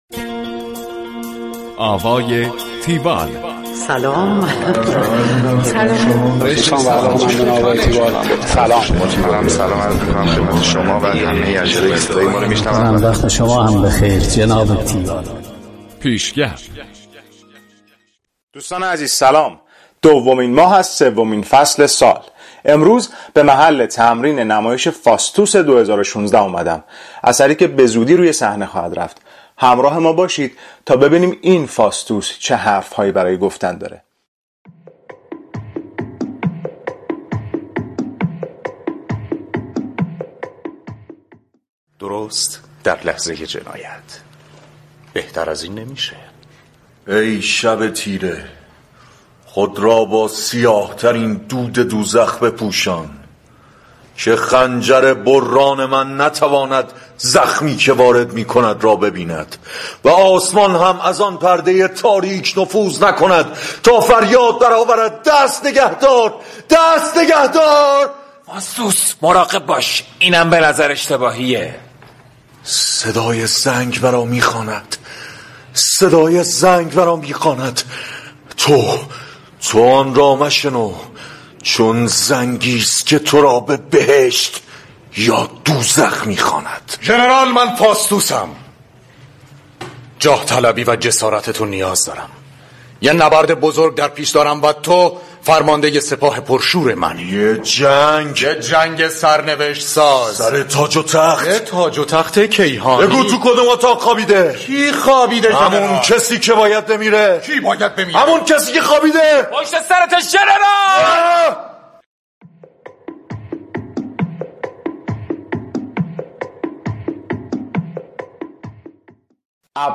گزارش آوای تیوال از نمایش فاستوس ۲۰۱۶